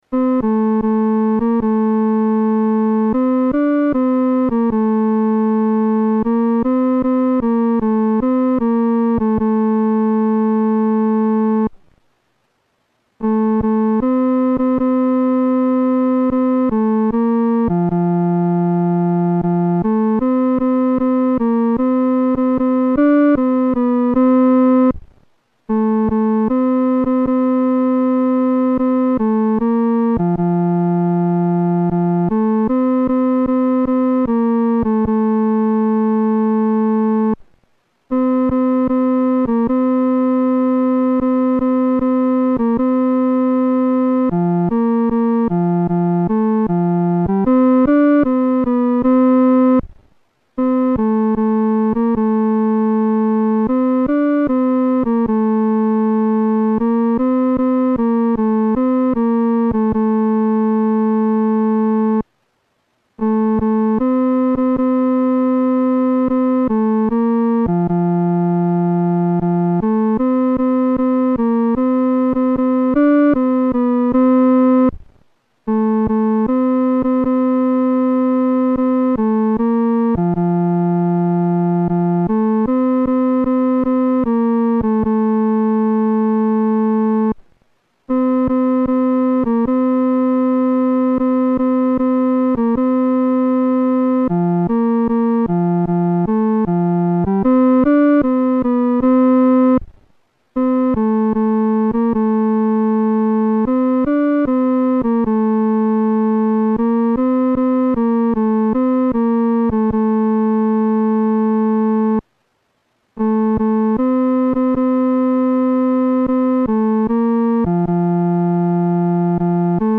伴奏
男高
本首圣诗由网上圣诗班 (青草地）录制
这首十分活跃的诗歌，表现出信徒经历主的恩典，欢喜快乐的心情。速度可用中速。